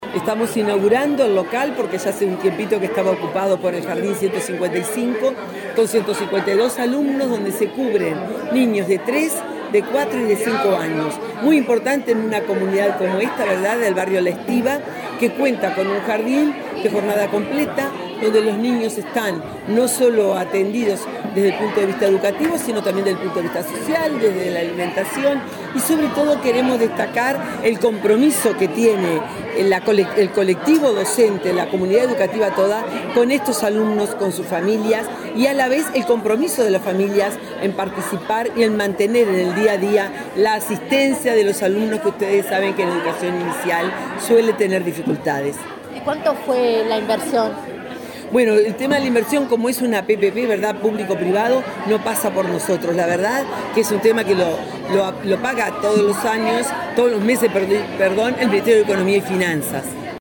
Declaraciones de la directora general de Primaria, Graciela Fabeyro